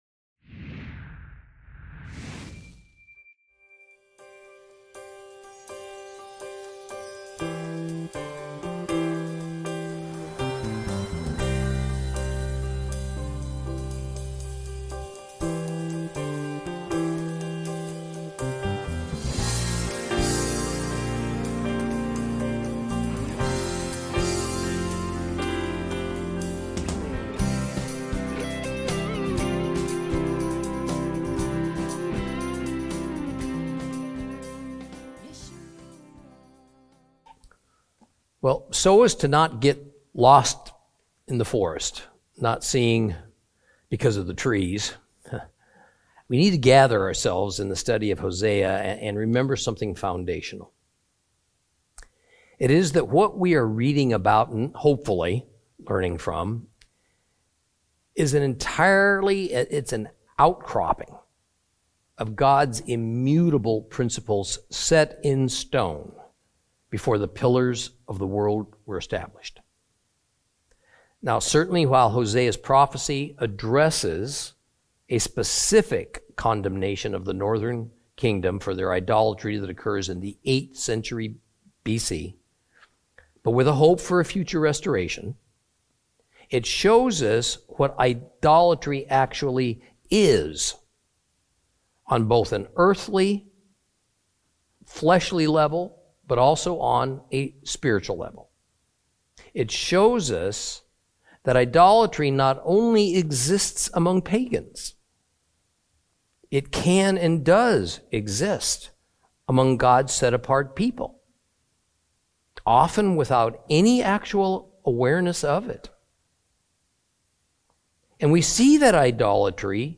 Teaching from the book of Hosea, Lesson 16 Chapter 9.